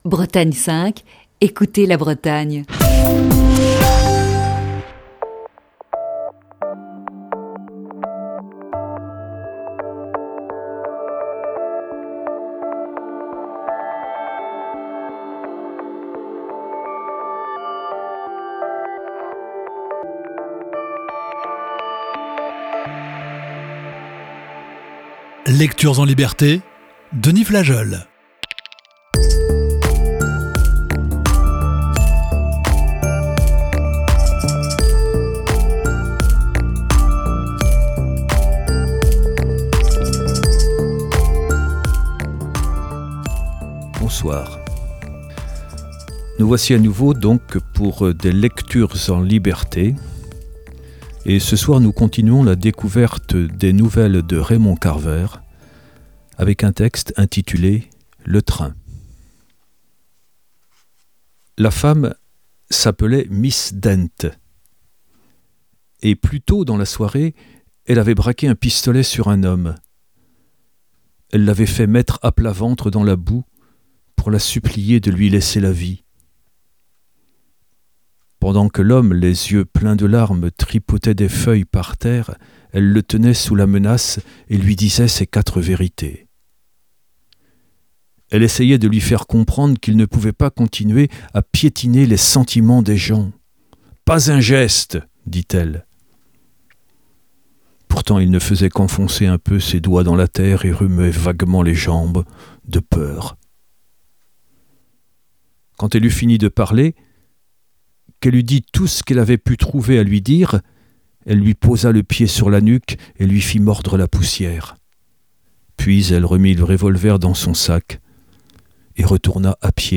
Ce soir, il lit la seconde partie des "vitamines du bonheur" et "Le train". (Émission diffusée initialement le 18 octobre 2019).